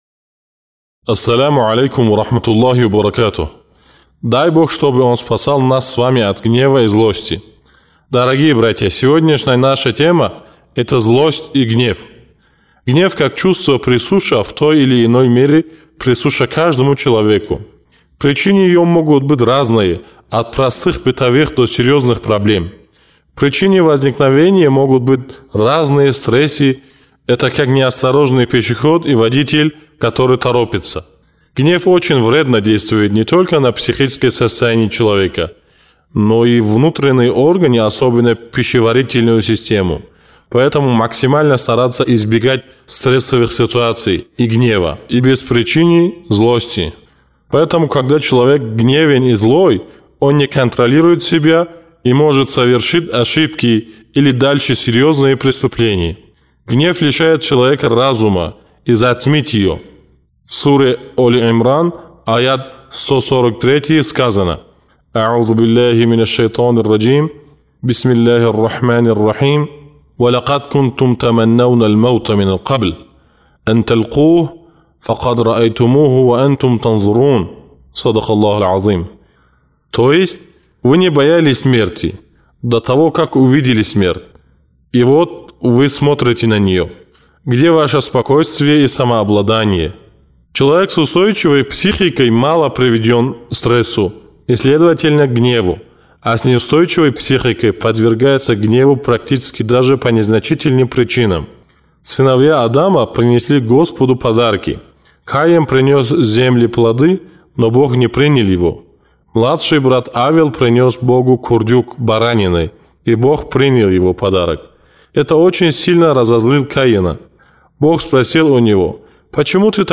Чтобы найти ответы на все эти вопросы, вы можете прослушать четвертую речь – «Гнев приносит обществу трудности».